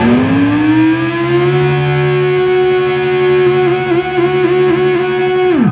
DIVEBOMB17-L.wav